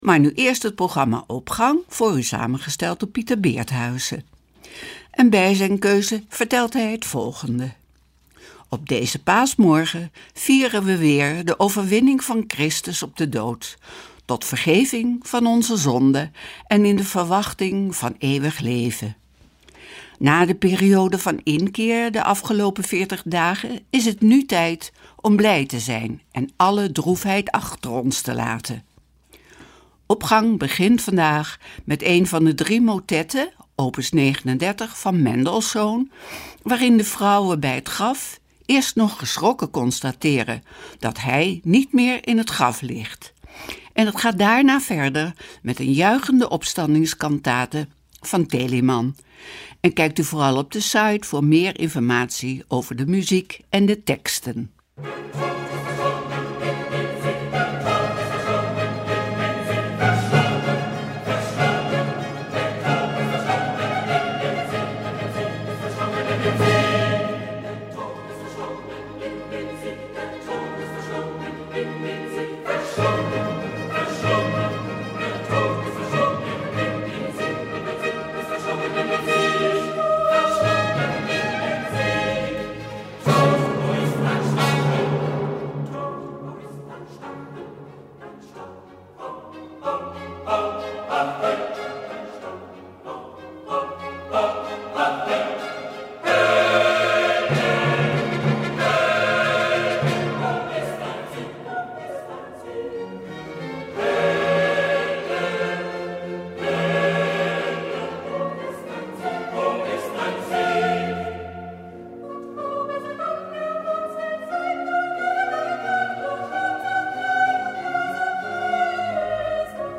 Opening van deze Paaszondag met muziek, rechtstreeks vanuit onze studio.
motetten
juichende opstandingscantate